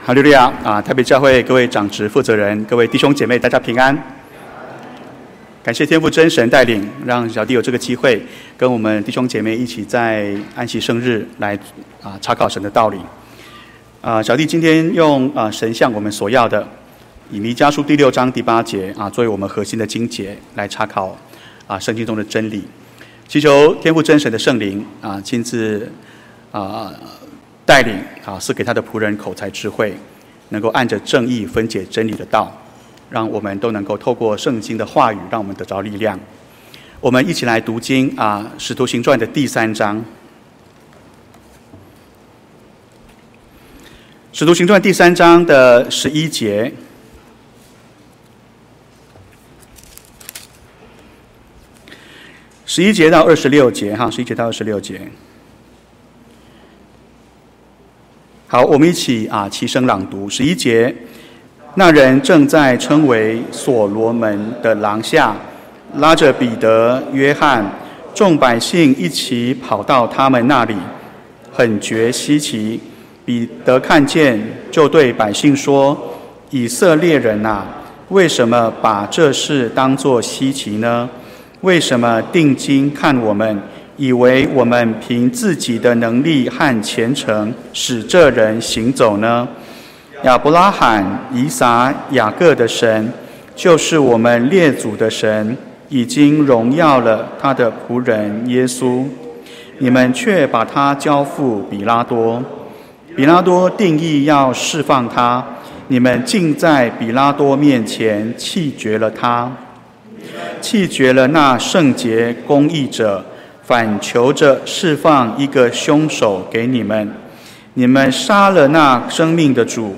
神向我們所要的（下）-講道錄音